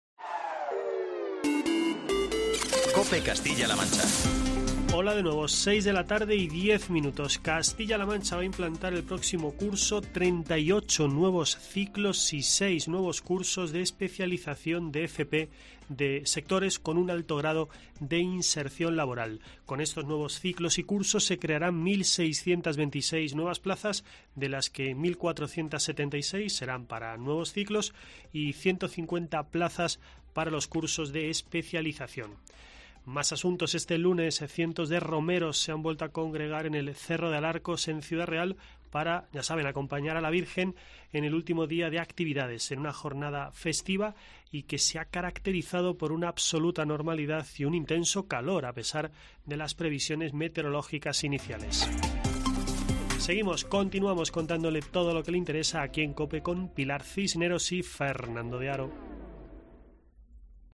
boletín informativo de COPE Castilla-La Mancha